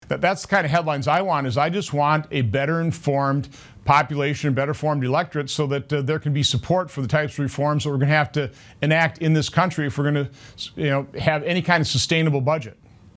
Senator Johnson gave these answers during an interview on Wednesday, Nov. 12, with WBAY-TV.